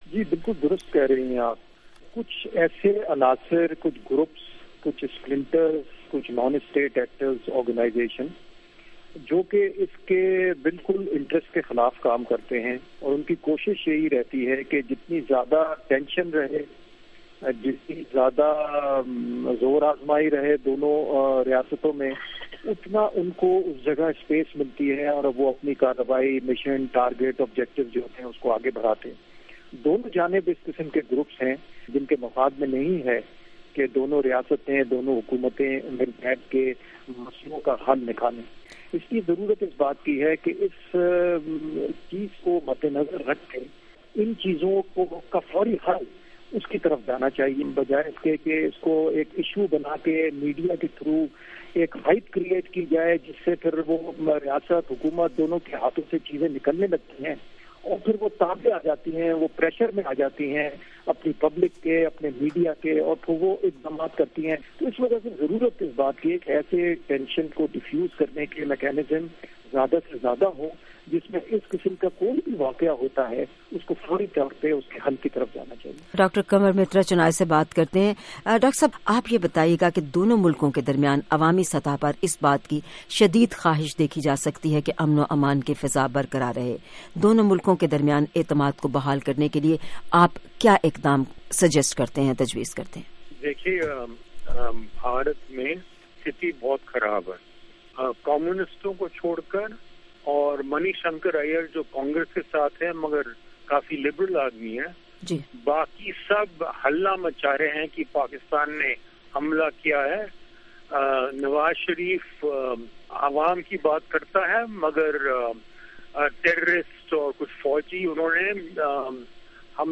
بھارت پاکستان تعلقات، نامور تجزیہ کاروں سے بات چیت